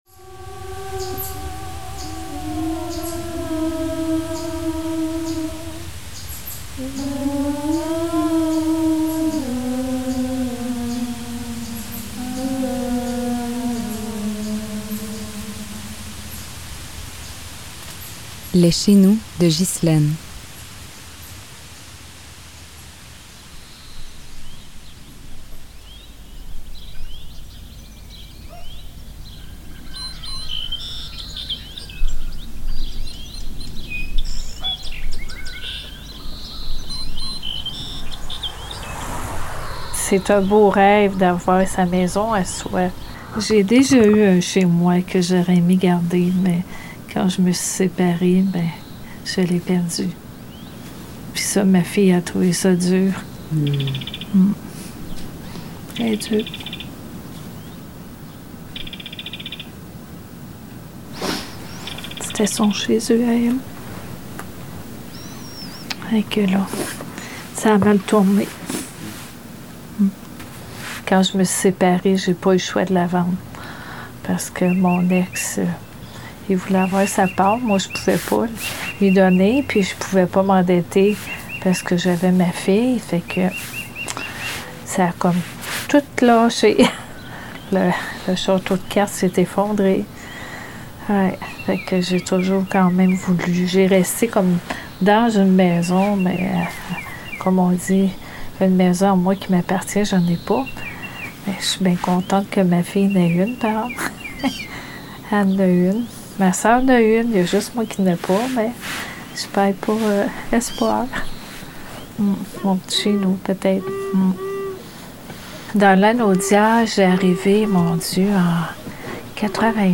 Une cocréation documentaire de
En conversation avec